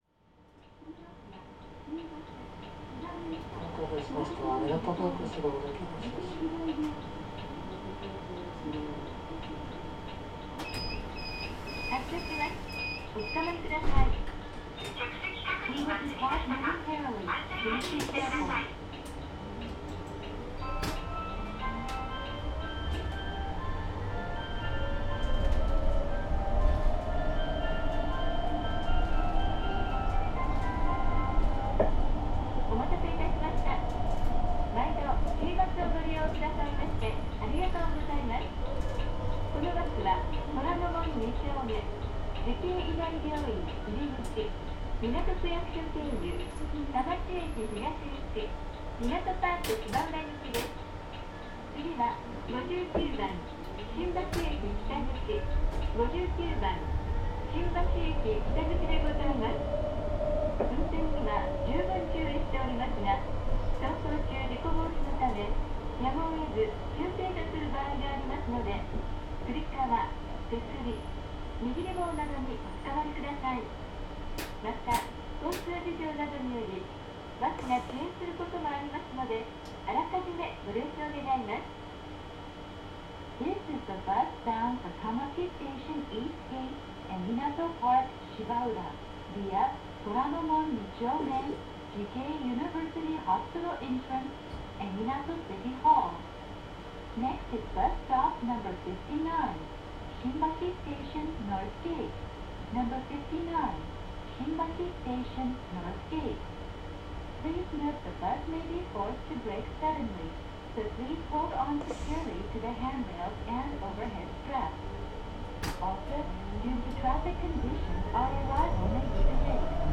フジエクスプレス EVモーターズ・ジャパン WSD6690BR2EV ・ 走行音(全区間)(その1) (66.4MB★) ←new!!! 収録区間：ちぃばす 芝ルート 新橋駅→みなとパーク芝浦 ・ 走行音(全区間)(その2) (65.2MB★) ←new!!! 収録区間：ちぃばす 芝ルート 新橋駅→みなとパーク芝浦 EVモーターズ・ジャパン製の小型電気バスで、港区コミュニティバス、ちぃばすの芝ルート専属で運行されている。
導入時期により？走行音が異なるようで、収録した2台ではそれぞれでモーター音やドアブザーの音色などが異なっている。